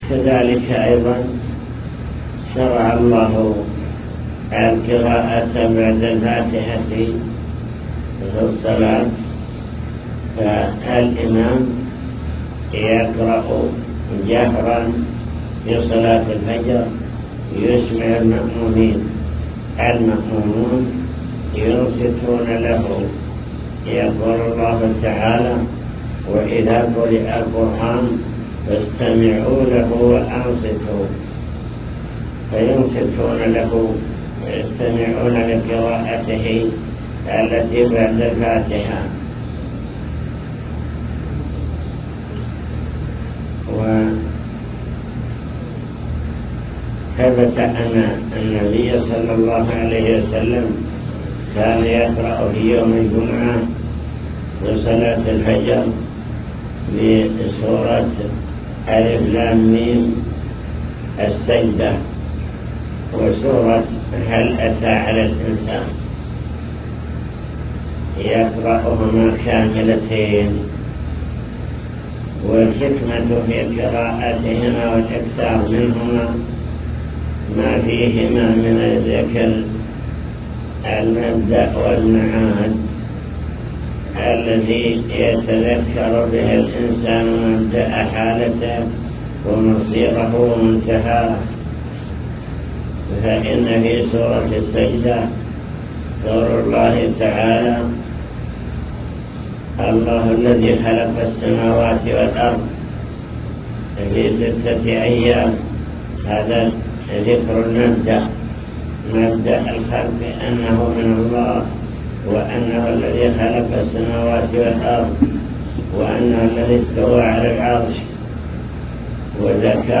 المكتبة الصوتية  تسجيلات - لقاءات  حول أركان الصلاة (لقاء مفتوح)